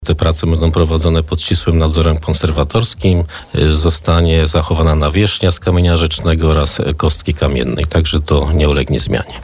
Jak mówił na naszej antenie wiceprezydent miasta, finisz – zgodnie z umową – ma nastąpić w lipcu